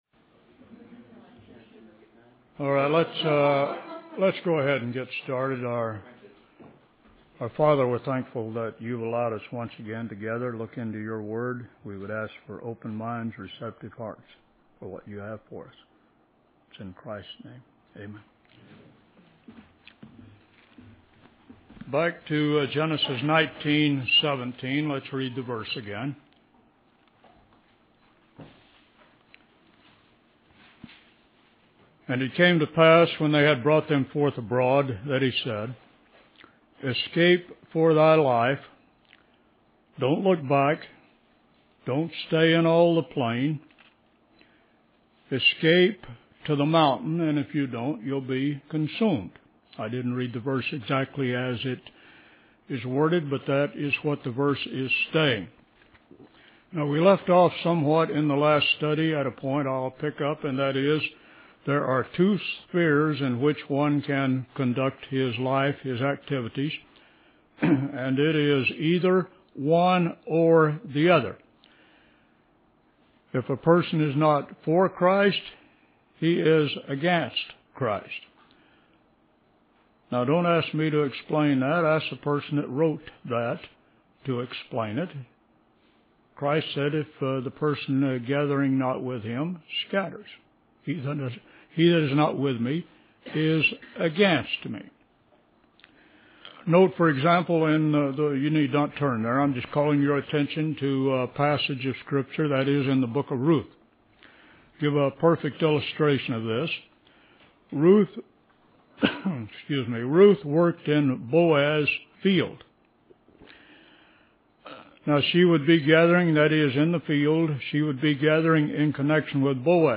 In this sermon, the preacher emphasizes the importance of not dwelling in the plain, using the story of Lot's wife as an example. He contrasts Lot, who didn't make it far in his journey, with Abraham, who dwelled in the high country. The preacher warns against tying our hopes and visions to the present world system, as it will ultimately be destroyed.